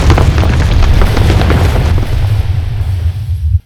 tremble.wav